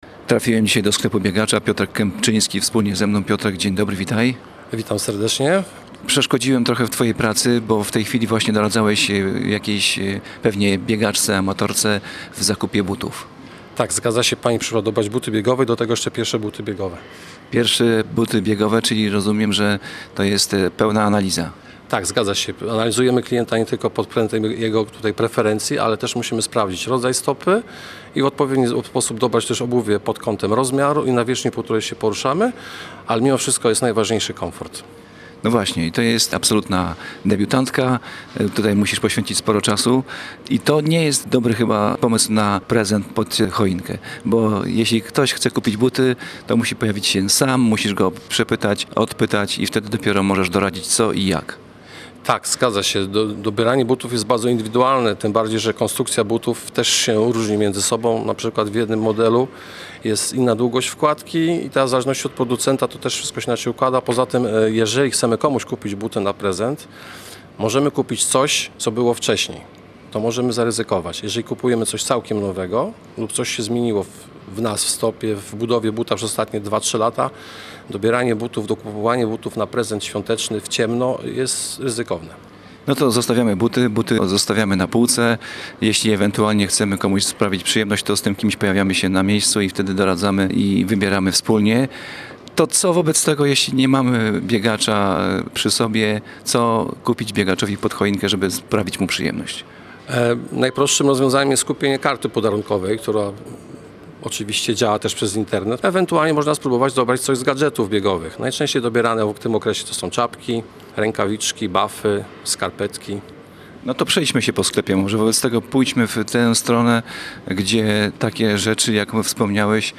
W audycji "Pomorze Biega i Pomaga" wybraliśmy się do sklepu dla biegaczy, aby kupić prezent pod choinkę dla osoby aktywnej.